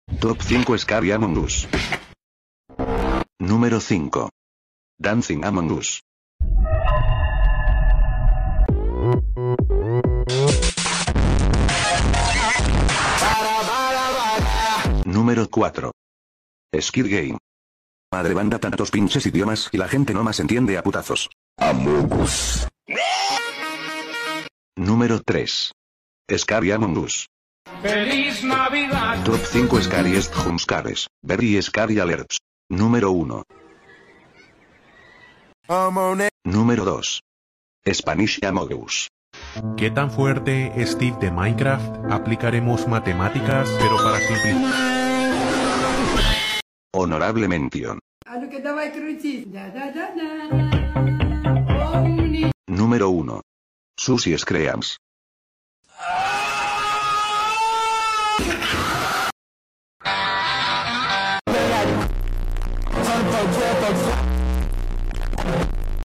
Top 5 Scary Among Us sound effects free download